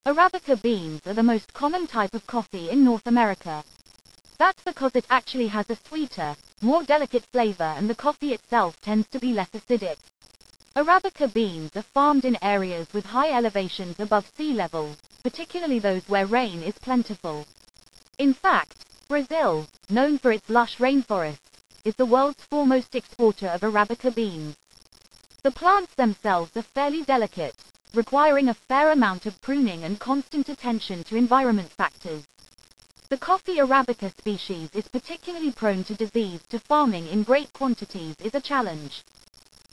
voiceover_1_5.wav